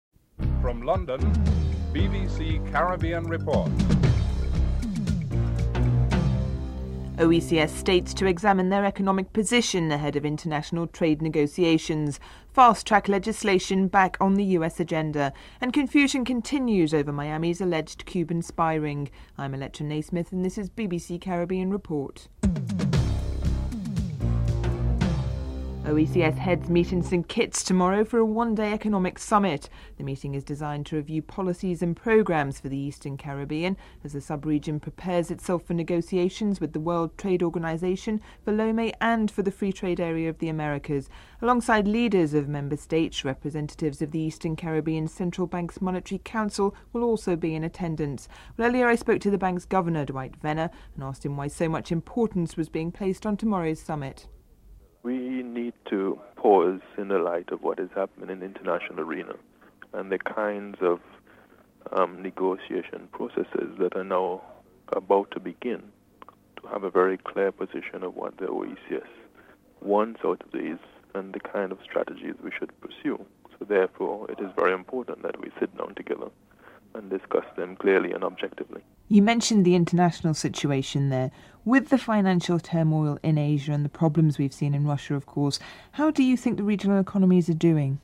2. OECS States are to examine their economic position ahead of the international trade negotiations. Eastern Caribbean Central Bank Governor Dwight Venner is interviewed (00:25-03:01)